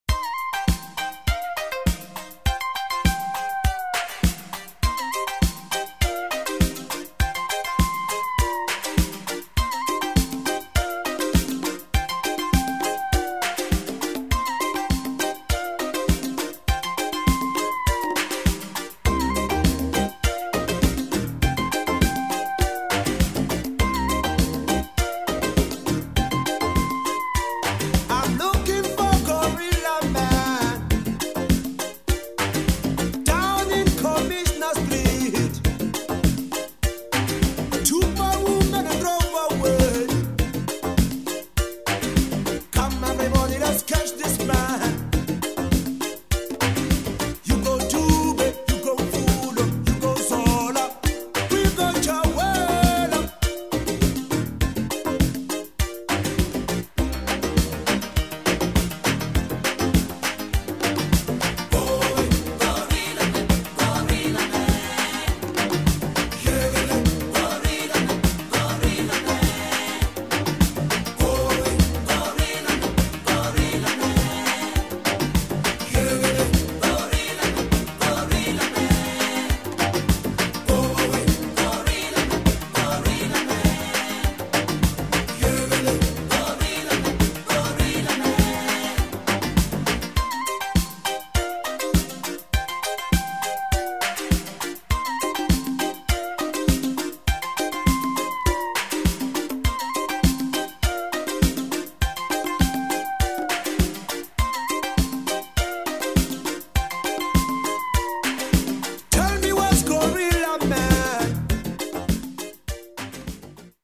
Afro electro funk mini album